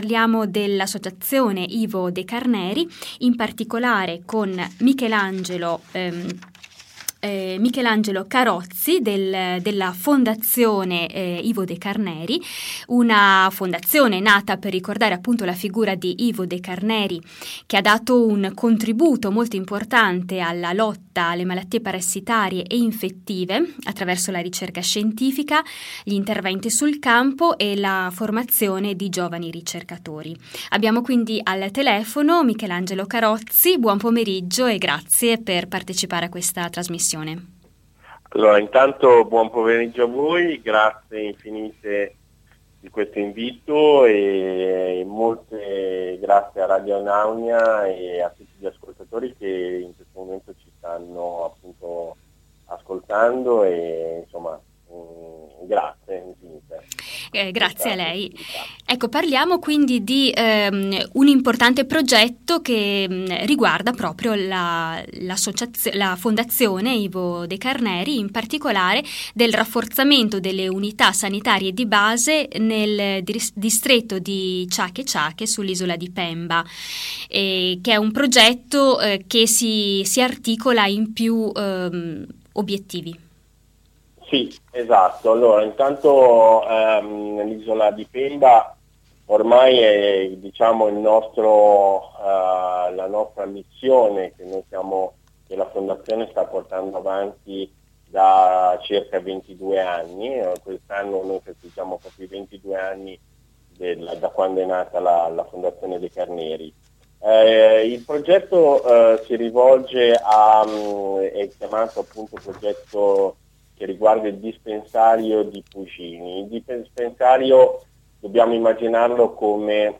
Interview on Radio Anaunia